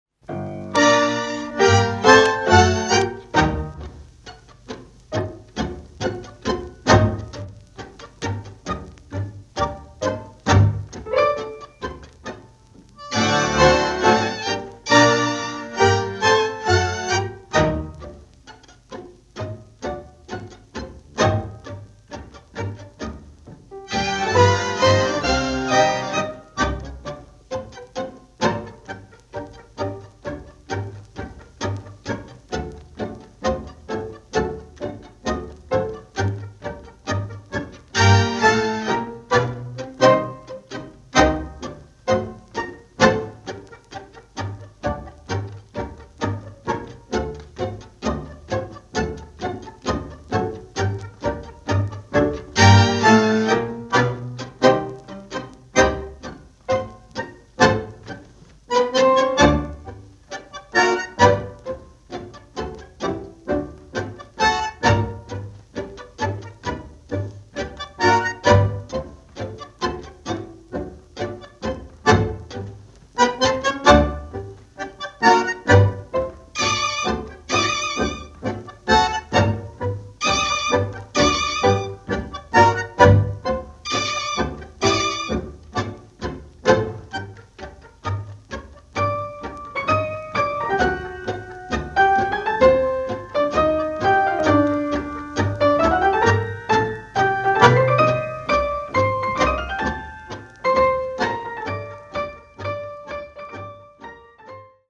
Pozdravljeni ljubitelji dobre tango glasbe!
instrumental